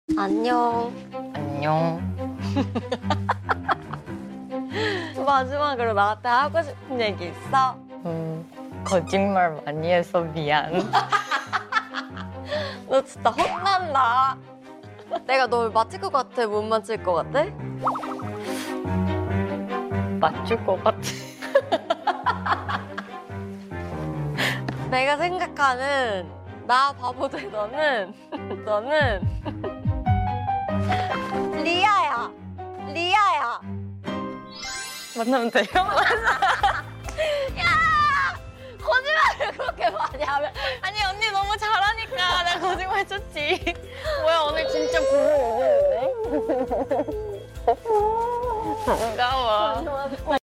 Everyone knows the sound of nayeon and lia voice, singing, and laugh.